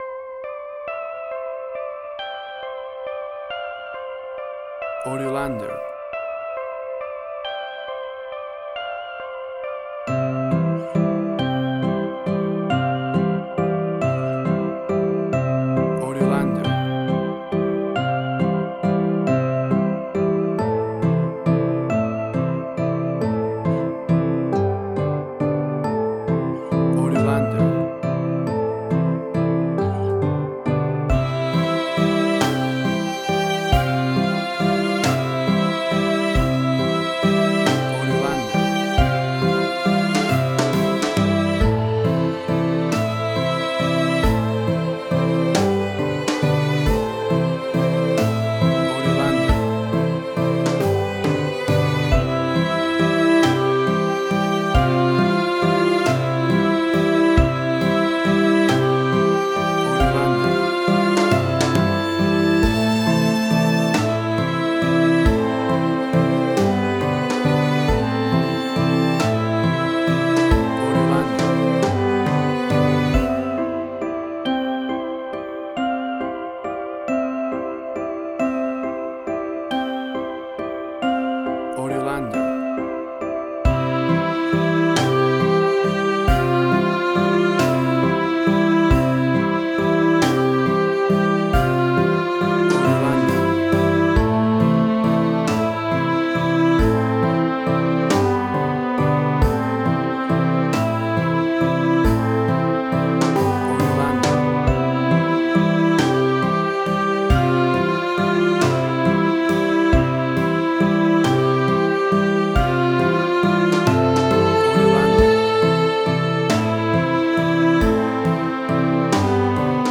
Suspense, Drama, Quirky, Emotional.
Tempo (BPM): 46